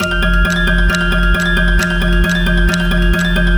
HORROR MUSIC BOX
A Ill Omened Clock_Urgent4.wav